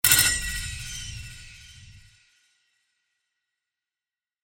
card_played_special.mp3